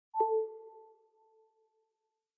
Звук подключения iPhone к зарядке